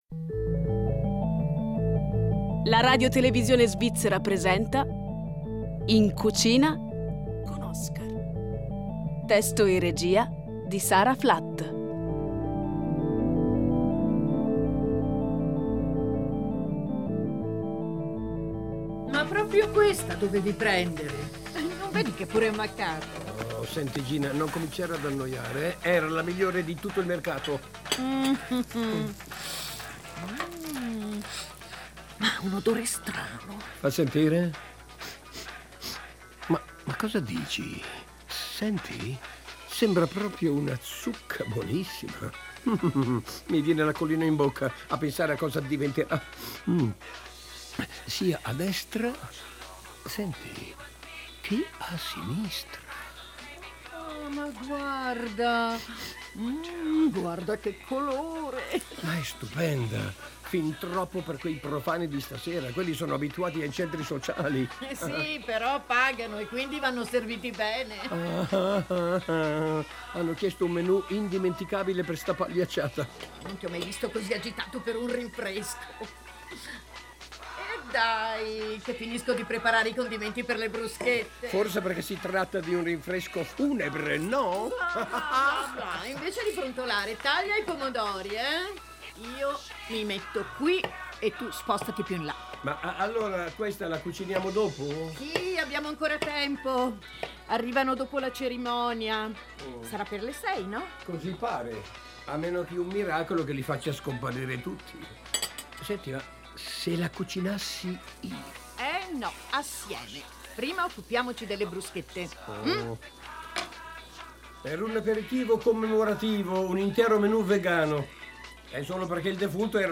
Colpo di scena Dalla parte di Oscar - In cucina con Oscar 02.06.2022 18 min Contenuto audio Disponibile su Scarica Immagina di essere una zucca protagonista di una storia che si svolge in una cucina durante il ricevimento per un funerale….. da sentire con le cuffie.